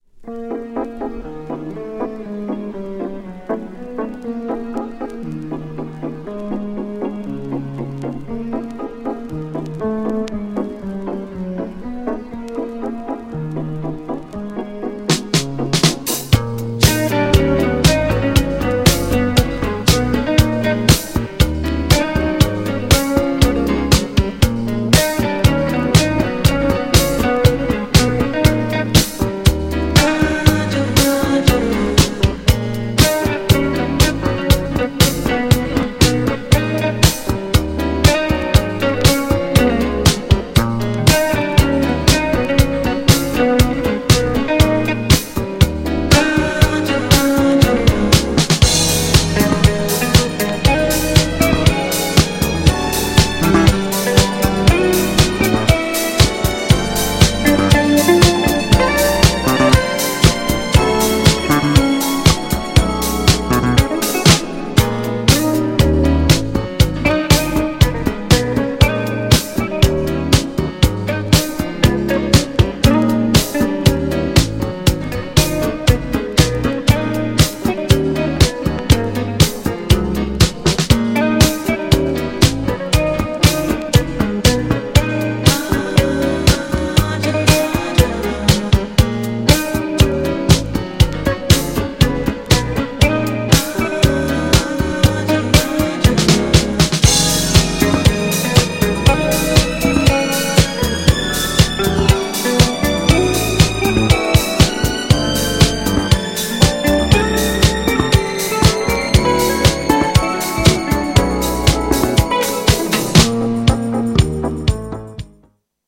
GENRE Dance Classic
BPM 111〜115BPM
FUSION
ミディアム # メロウ